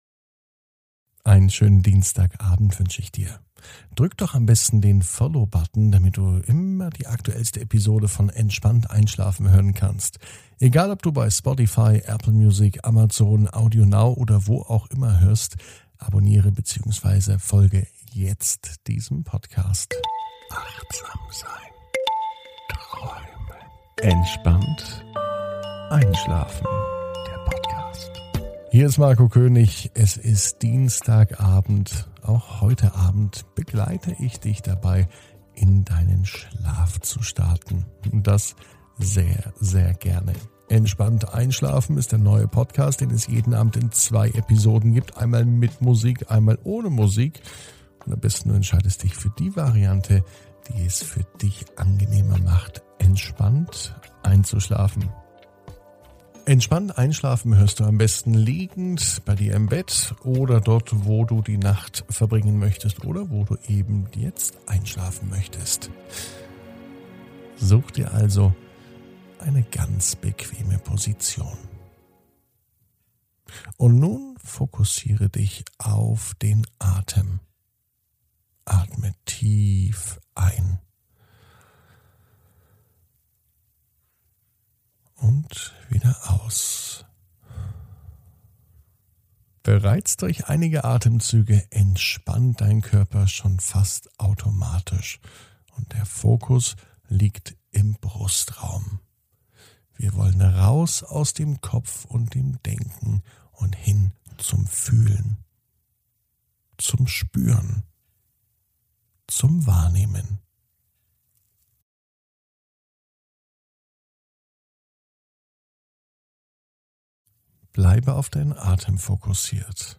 (ohne Musik) Entspannt einschlafen am Dienstag, 27.04.21 ~ Entspannt einschlafen - Meditation & Achtsamkeit für die Nacht Podcast